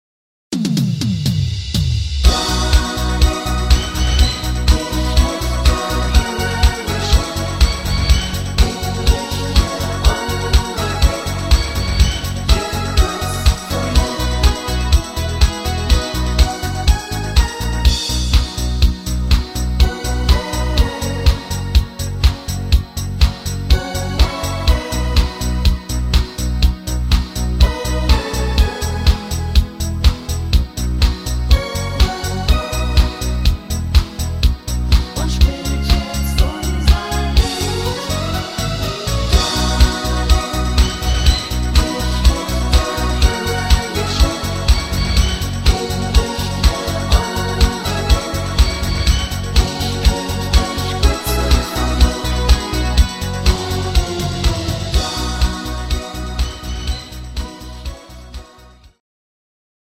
Rhythmus  8 Beat Discofox
Art  Deutsch, Fasching und Stimmung